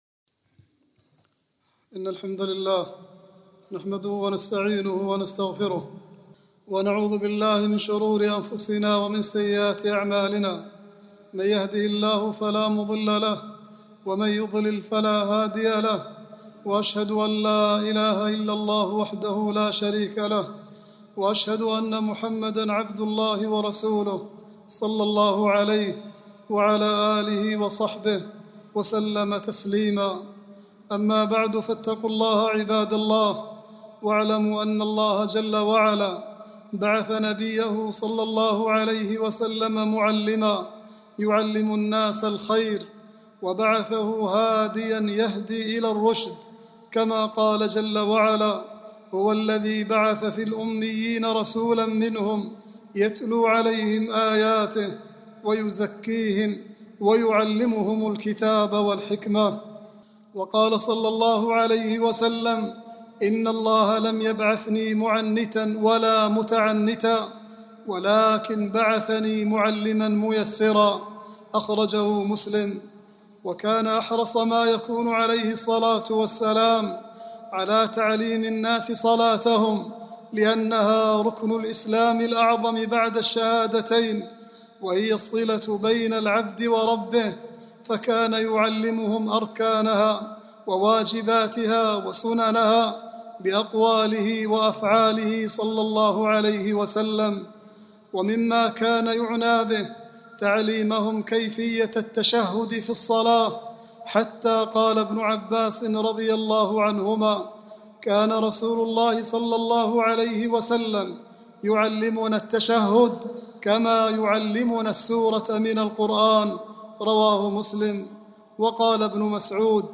khutbah-5-3-39.mp3